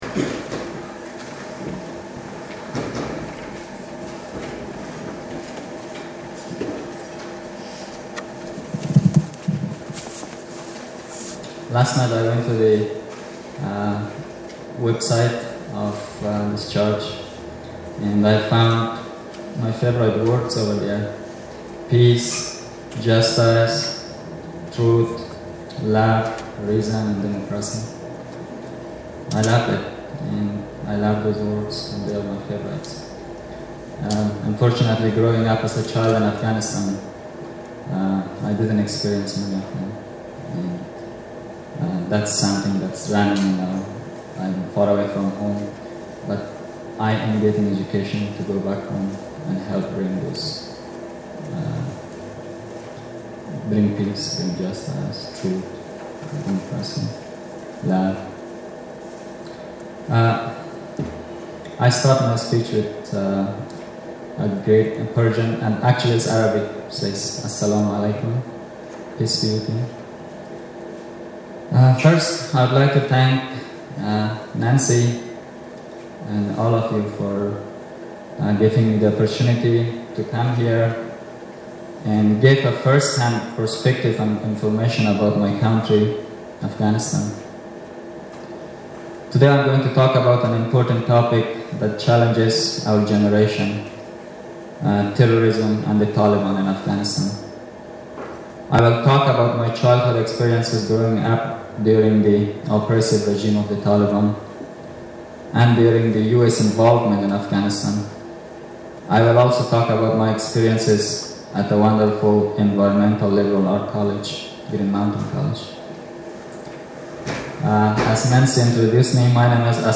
Posted in Sermons | Leave a Comment »